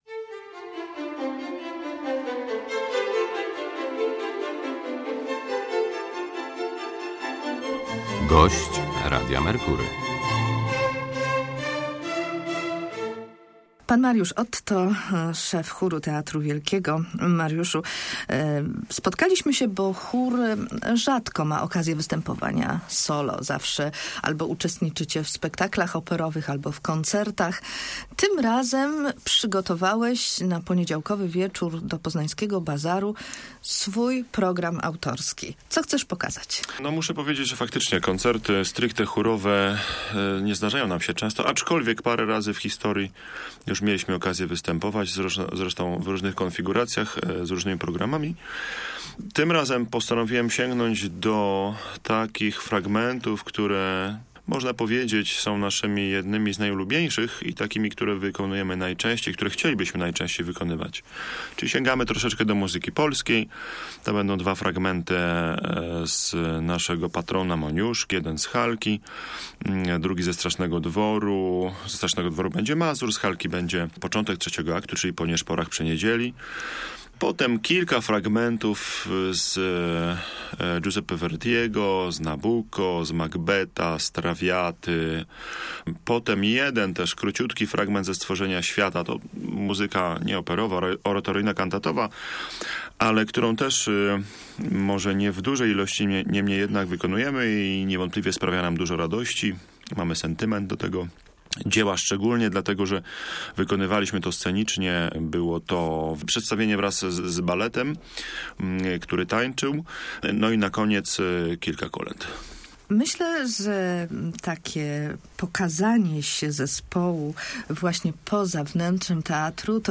Chór Teatru Wielkiego im. Stanisława Moniuszki w Poznaniu wystąpił w poniedziałek w pięknych wnętrzach Bazaru Poznańskiego.